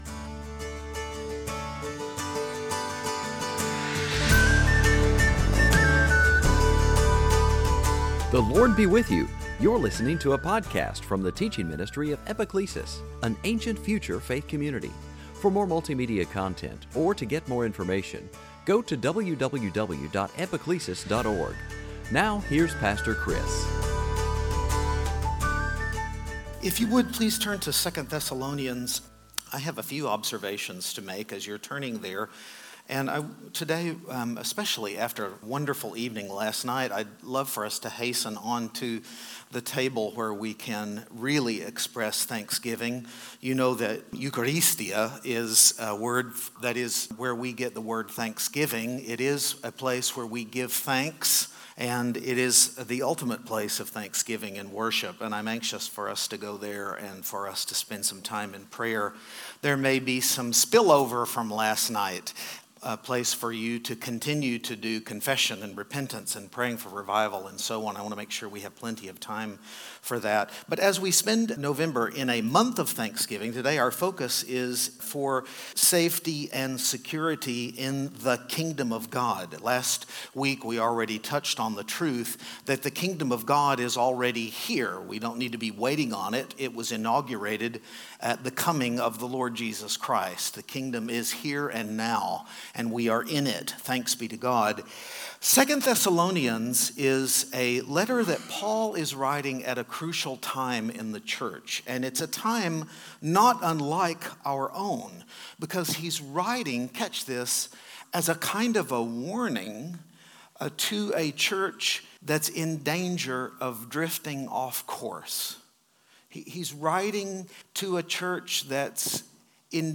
Sunday Teaching